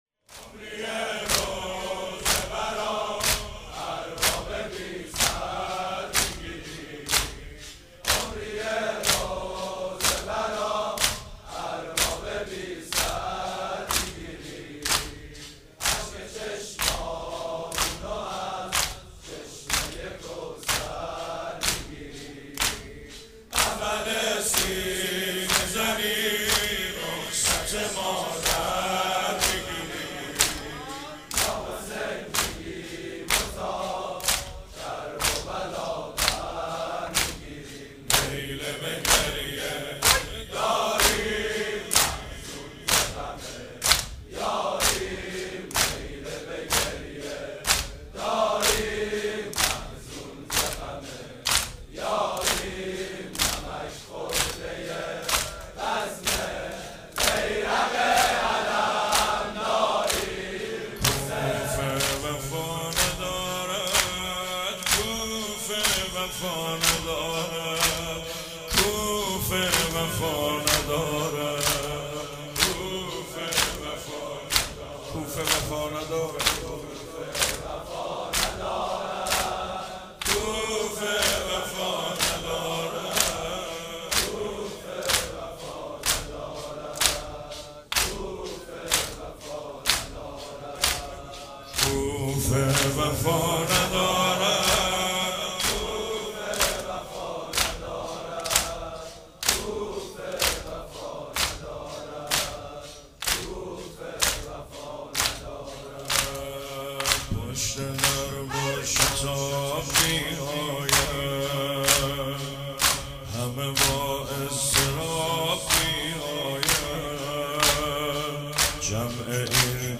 چهاراه شهید شیرودی حسینیه حضرت زینب (سلام الله علیها)
روضه